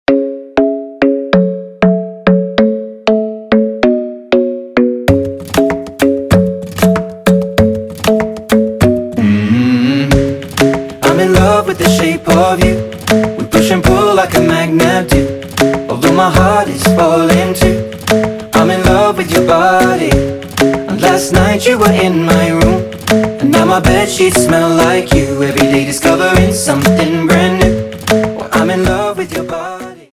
Dancehall , Ритмичные
Поп